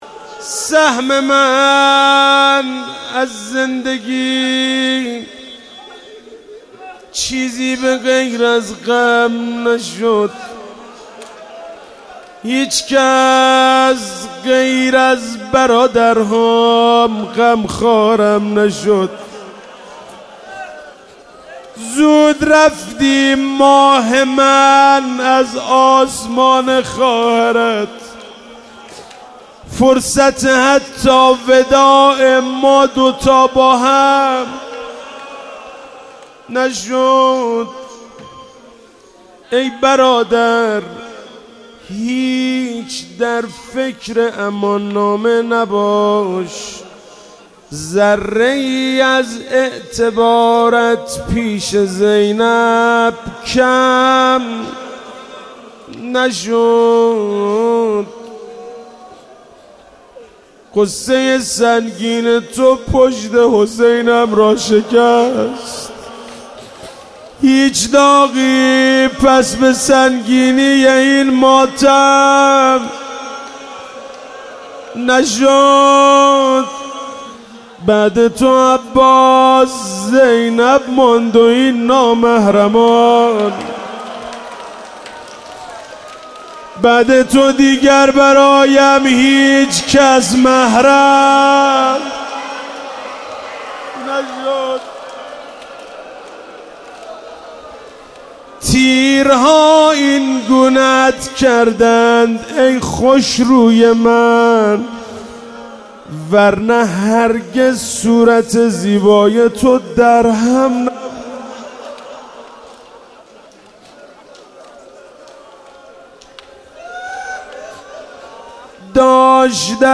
شب تاسوعا محرم95/هیئت انصارالحجه عج الله (مشهد)